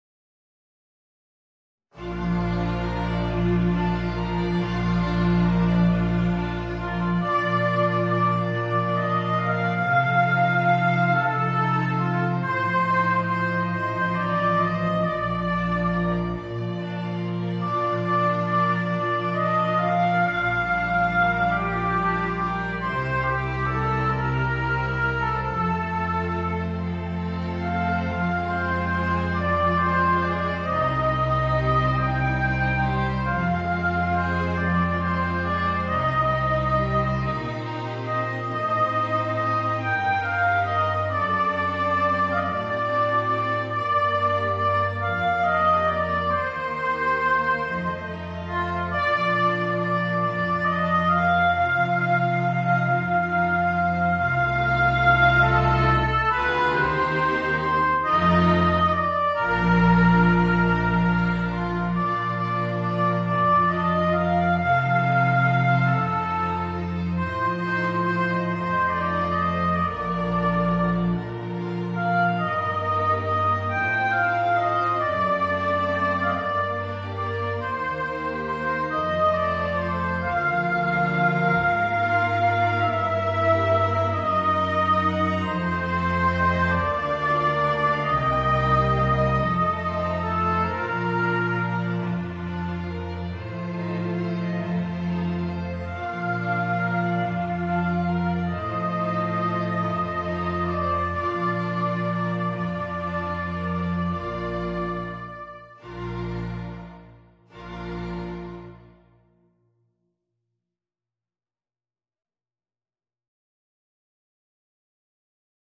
Voicing: Tenor Recorder and String Quintet